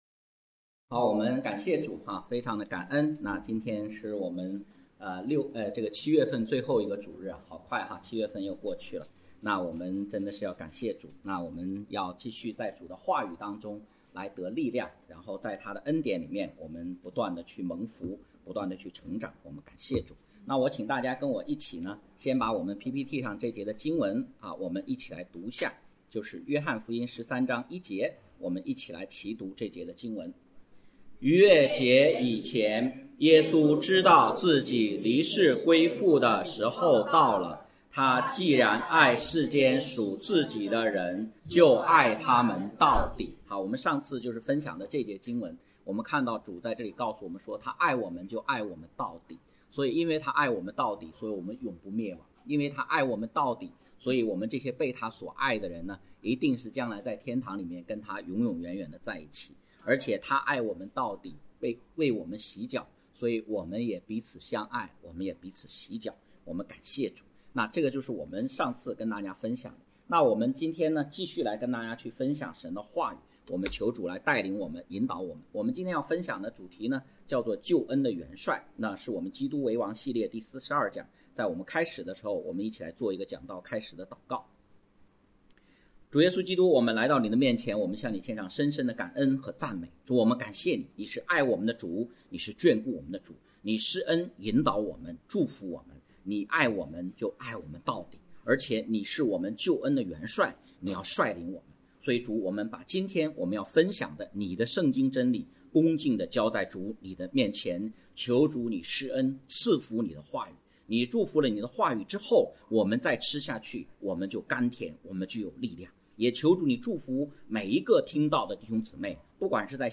《基督为王》系列讲道汇总